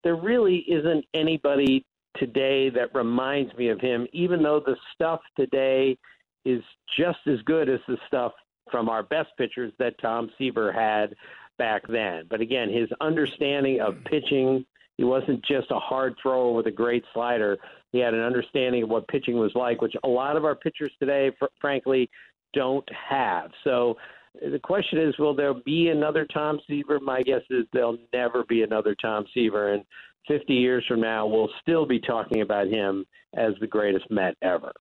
ESPN Sports Analyst Tim Kurkjian says there will never be another Tom Seaver.